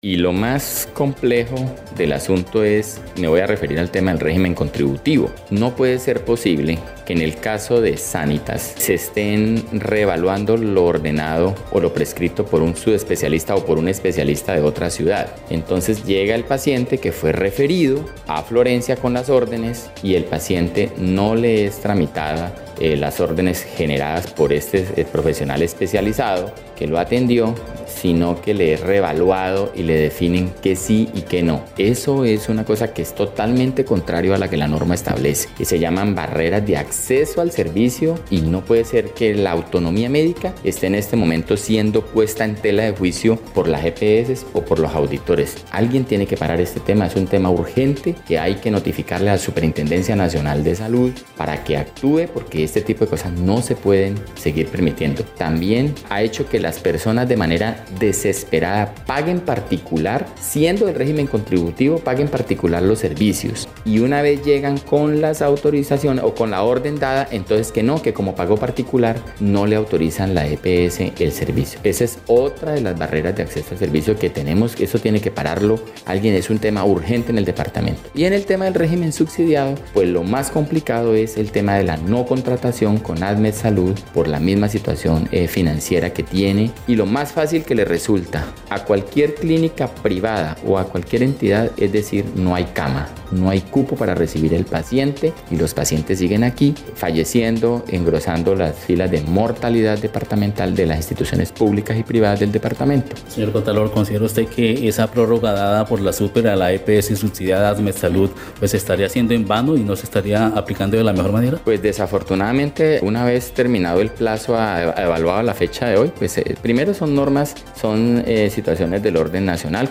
Así lo dio a conocer el Contralor Departamental, Hermes
01_CONTRALOR_HERMES_TORRES_NUÑES_SALUD.mp3